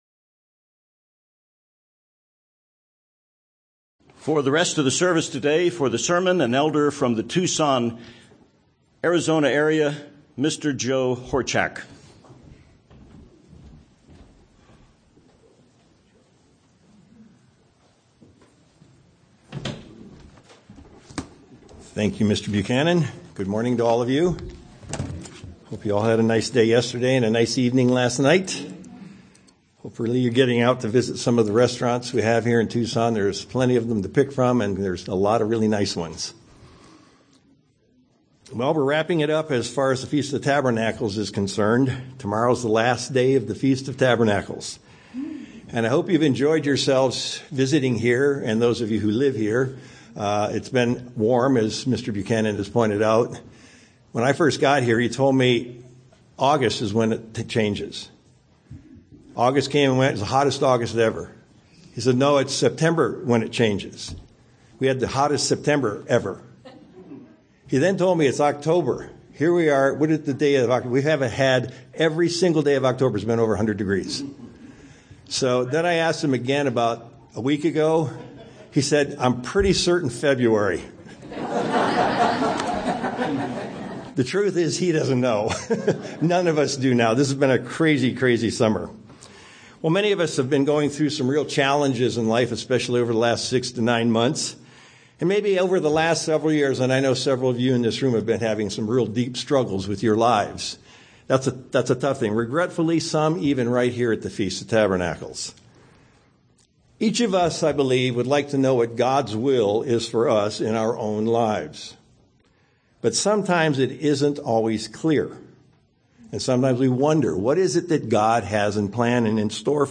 We will see in this sermon that everything that we go through now in life will be used by God and Christ and is not in vain. What we are experiencing today is what we may be teaching in the Millennium and beyond.
Given in Tucson, AZ